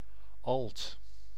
Ääntäminen
UK : IPA : /ˈæl.təʊ/ US : IPA : [ˈæl.təʊ] US : IPA : /ˈæl.toʊ/